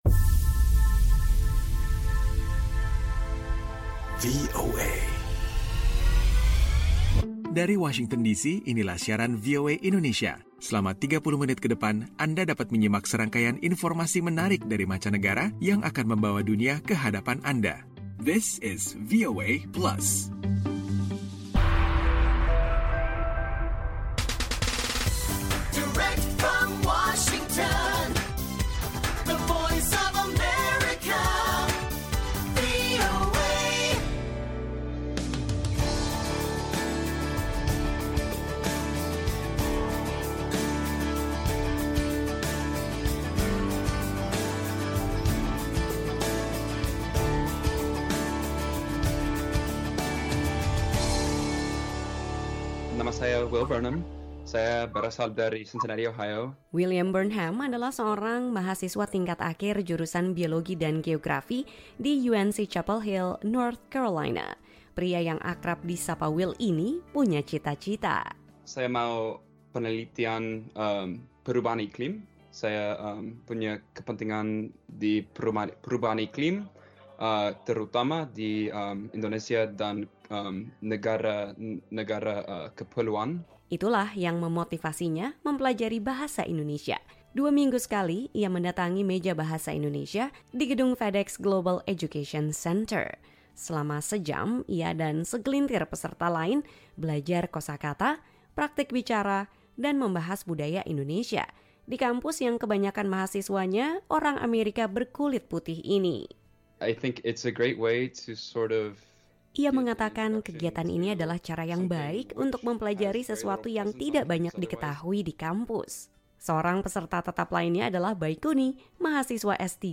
VOA Plus kali ini akan menggelar informasi seputar kegiatan belajar Bahasa Indonesia bagi mahasiswa Amerika yang tertarik dengan budaya Indonesia dalam sebuah diskusi meja. Ada pula info tentang kompetisi kecantikan kucing peliharaan di Brazil.